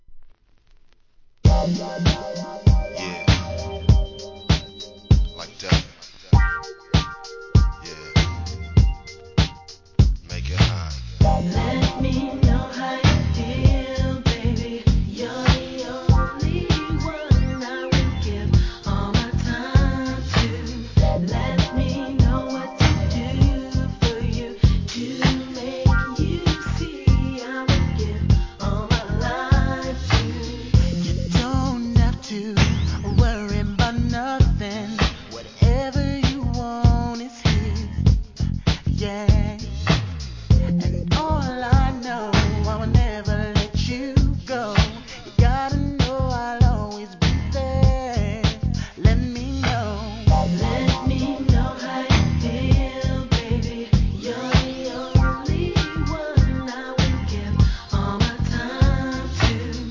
HIP HOP/R&B
落ち着いたヴォーカルワークから伸びやかに歌い上げるミッドチューン!